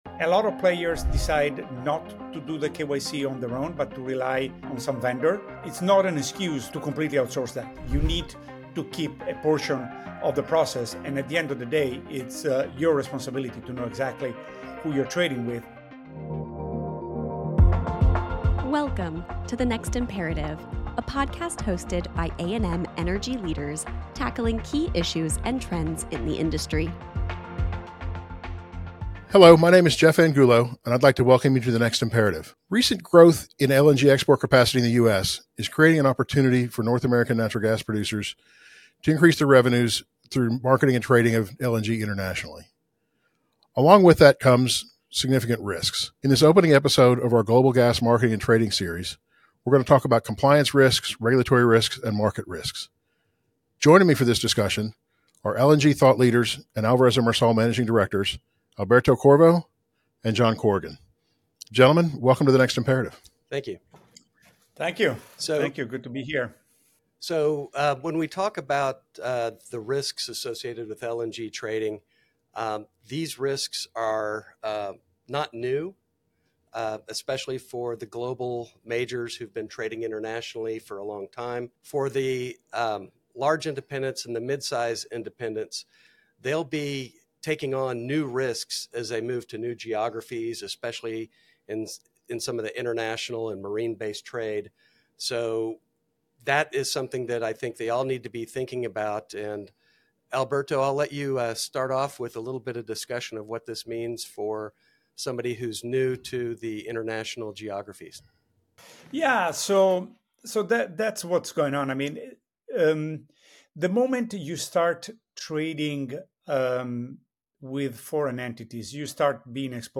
The conversation wraps up with insights on the challenges of Know Your Customer (KYC) and FCPA compliance, contract risks, and strategies for hedging inventory when shifting from immediate pipeline sales to managing weeks of inventory.